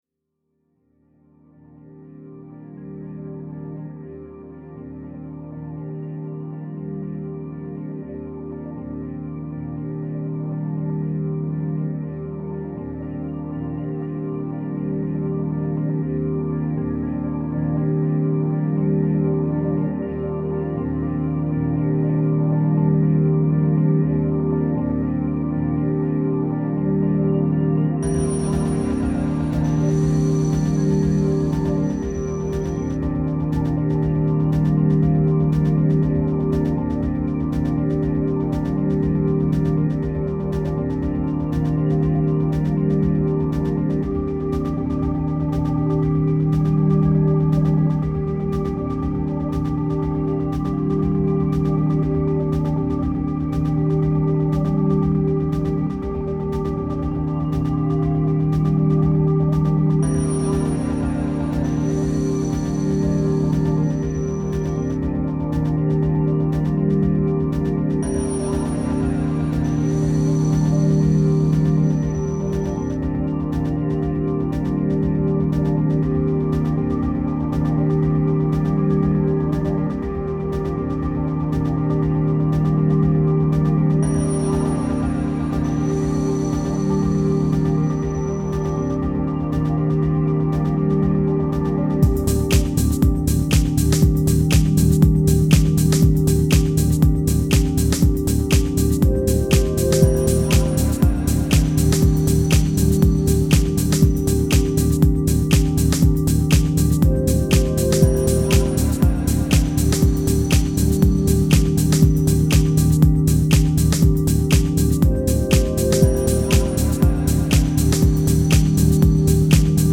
Upbeat/Melodic electronica instrumental